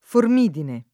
vai all'elenco alfabetico delle voci ingrandisci il carattere 100% rimpicciolisci il carattere stampa invia tramite posta elettronica codividi su Facebook formidine [ form & dine ] s. f. — latinismo ant. per «terrore»